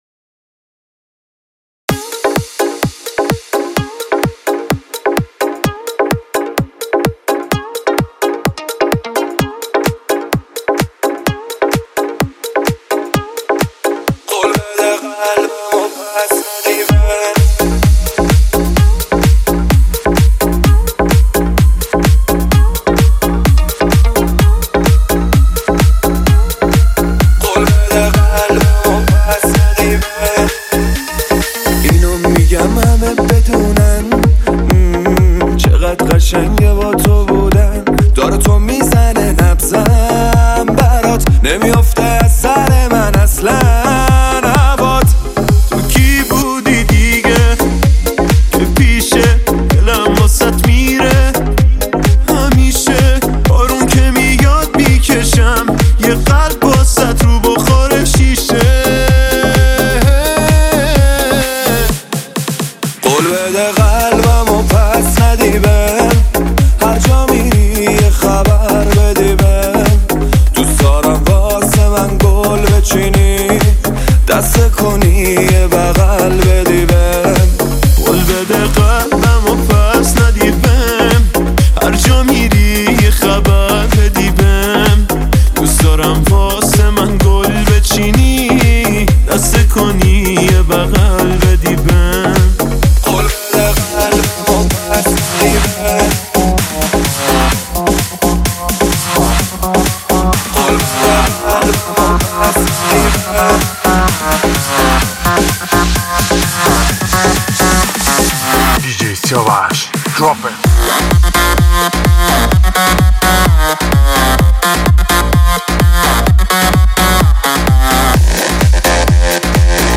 پاپ شاد رقص عاشقانه